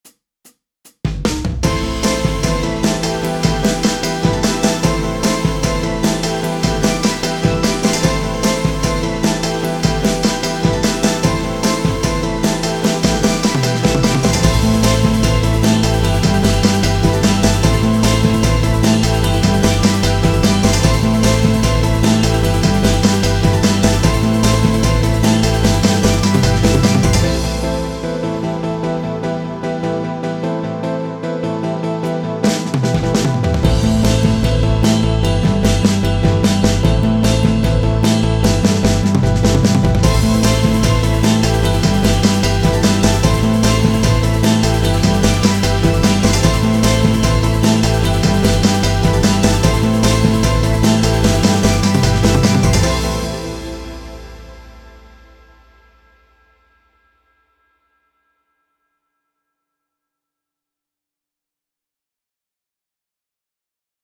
Buyrun size kıpır kıpır bir parça :) Dün yaptım taze taze sindirin :